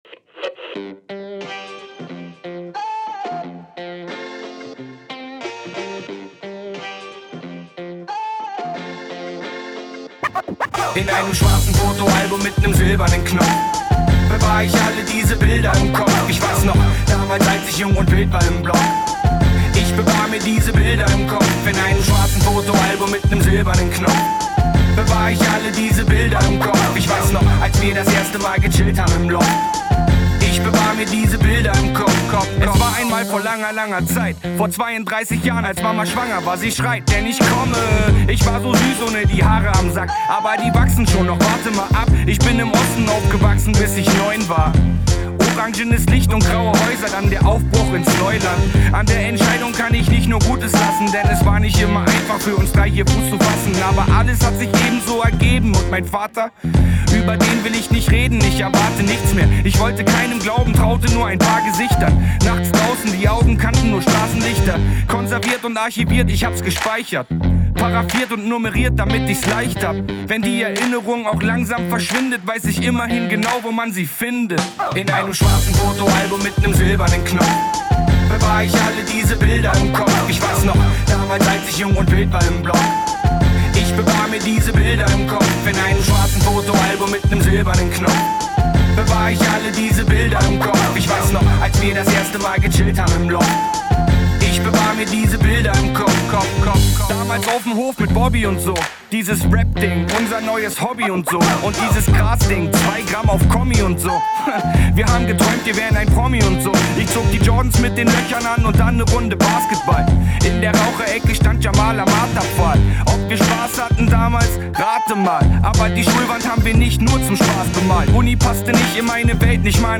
Hip Hop GER